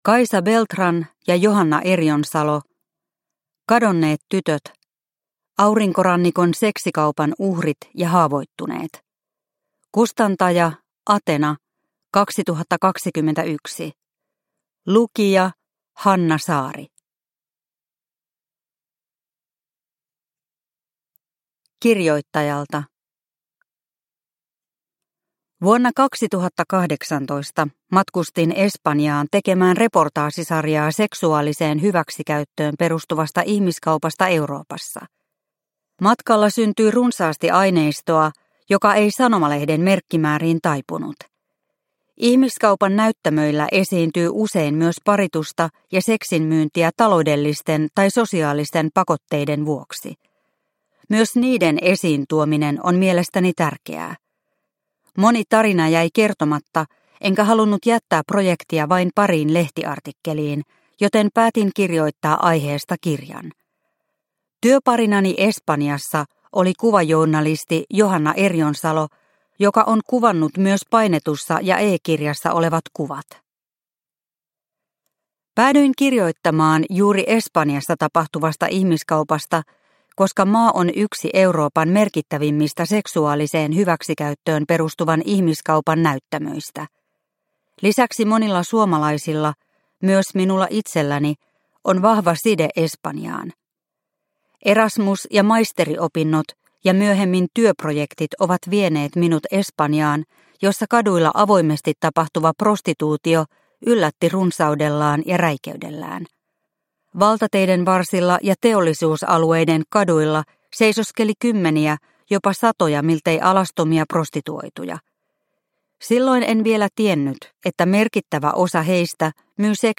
Kadonneet tytöt – Ljudbok – Laddas ner